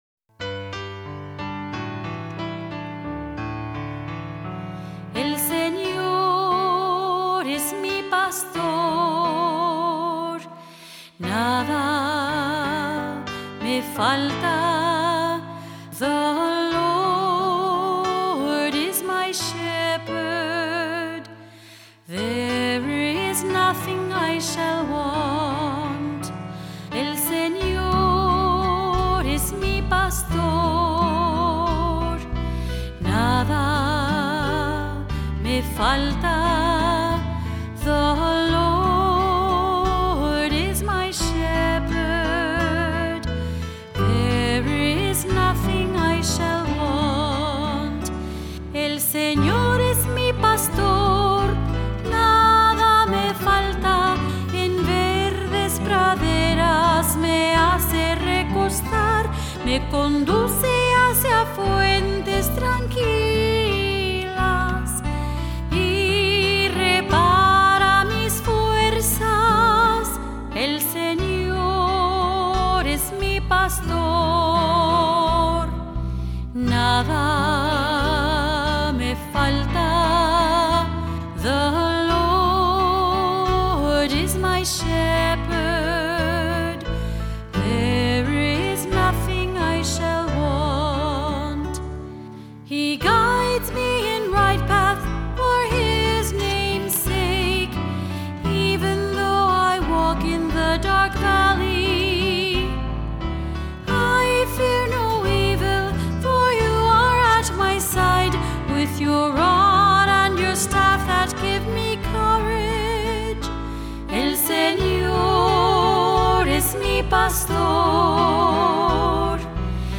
Voicing: Two-part choir; cantor(s); assembly